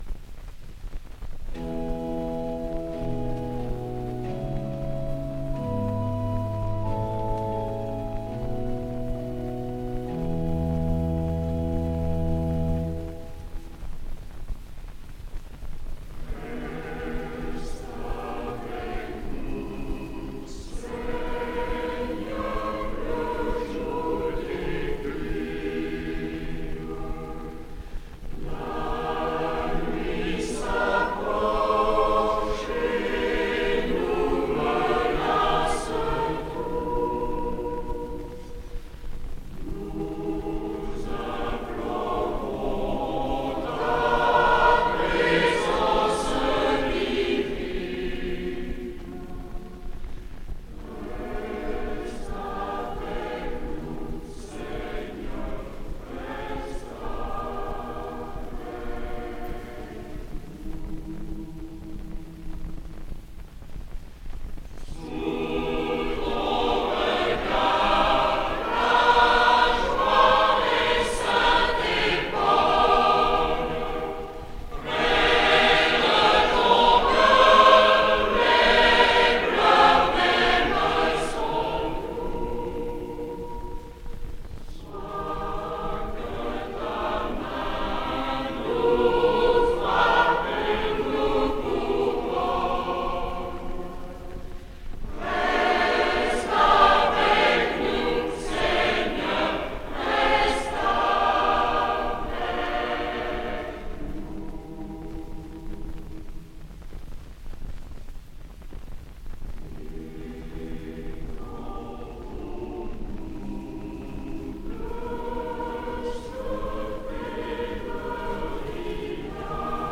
2020 CHANTS D'ÉGLISE audio closed https